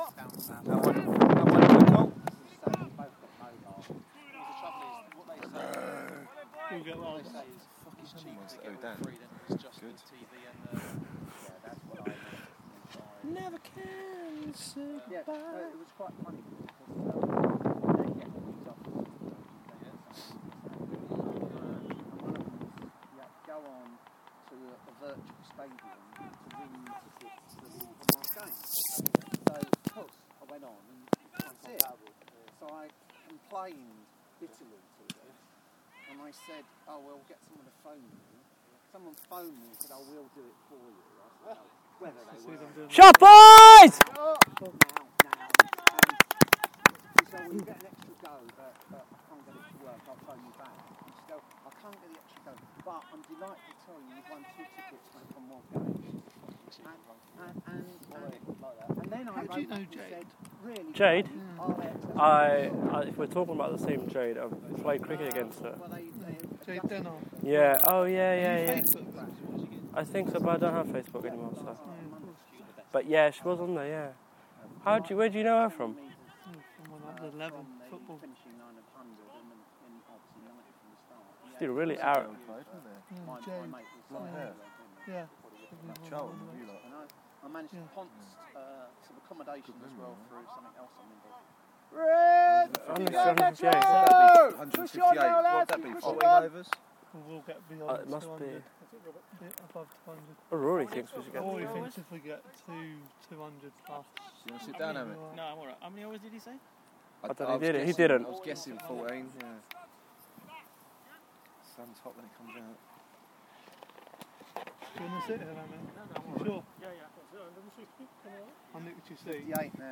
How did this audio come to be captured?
sounds of VI cricket on a sunny saturday in London